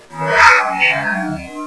like an older man saying.....
grand-child.wav